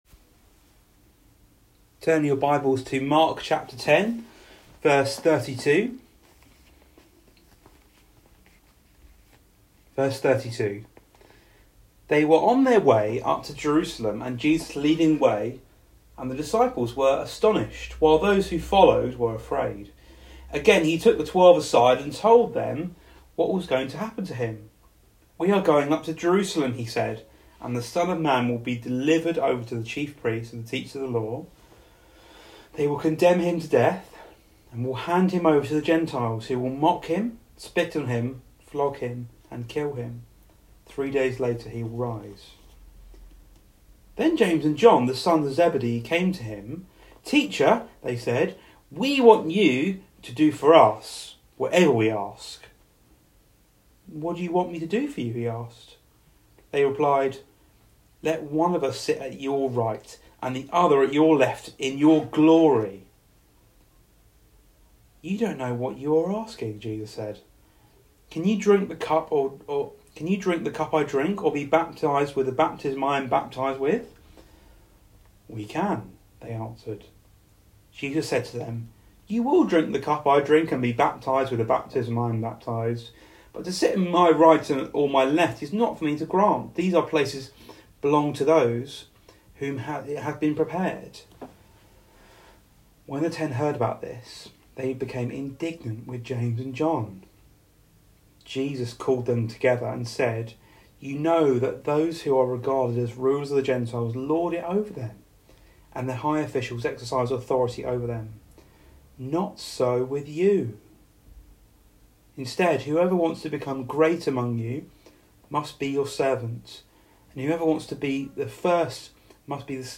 Passage: Mark 10:32-45 Service Type: Weekly Service at 4pm